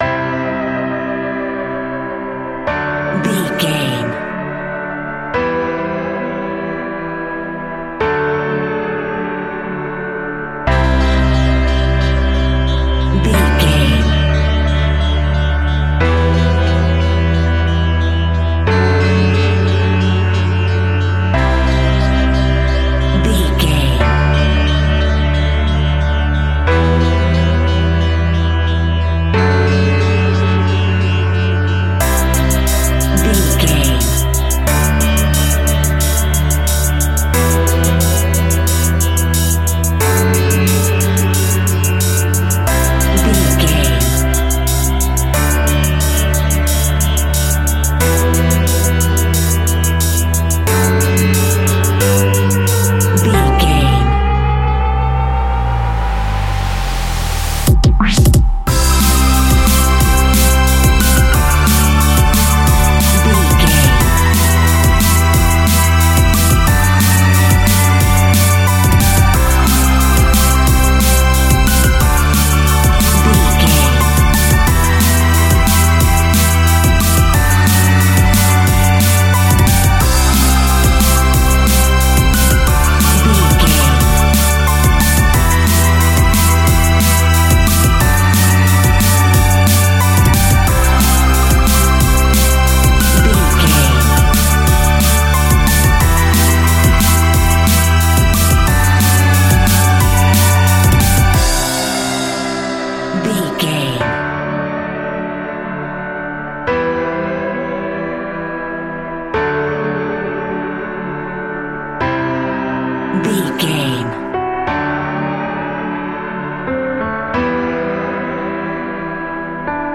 Ionian/Major
Fast
groovy
uplifting
futuristic
driving
energetic
repetitive
piano
synthesiser
drum machine
Drum and bass
electronic
instrumentals
synth bass
synth lead
synth pad
robotic